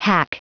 Prononciation du mot hack en anglais (fichier audio)
Prononciation du mot : hack